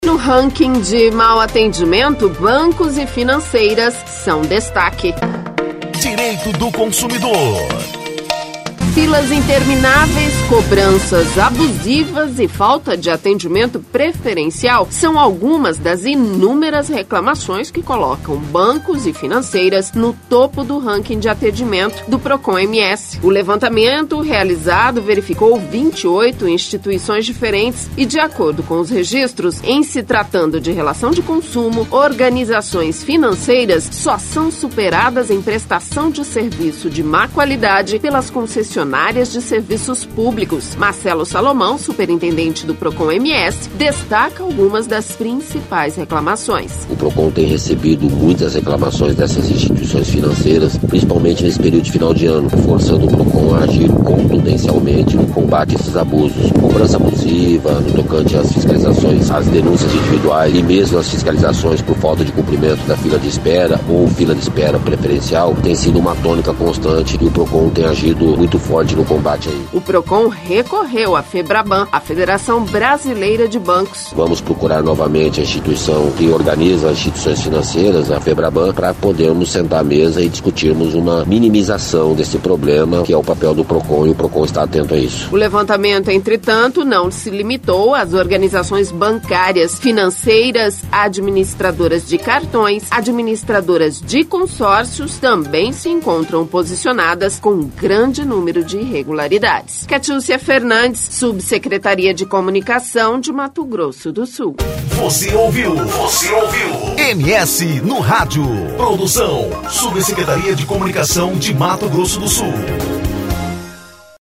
Marcelo Salomão, superintendente do Procon/MS, destaca algumas das principais reclamações.